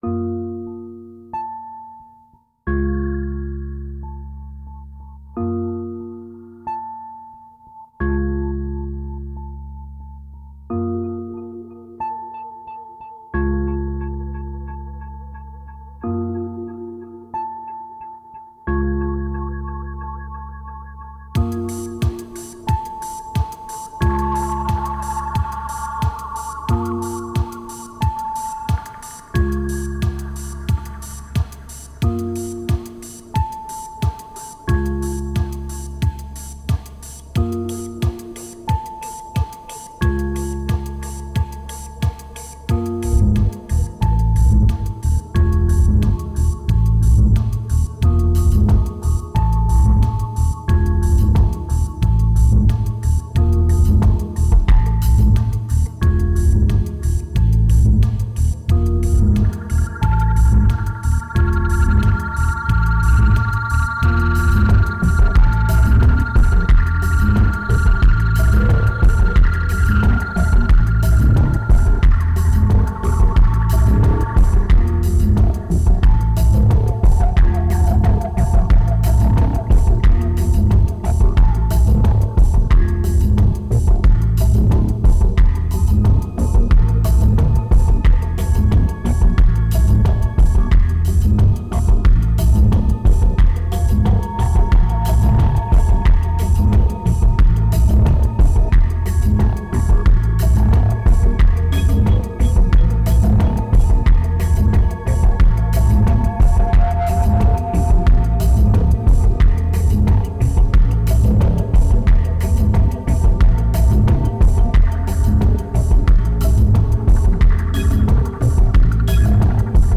2235📈 - 10%🤔 - 90BPM🔊 - 2010-06-20📅 - -142🌟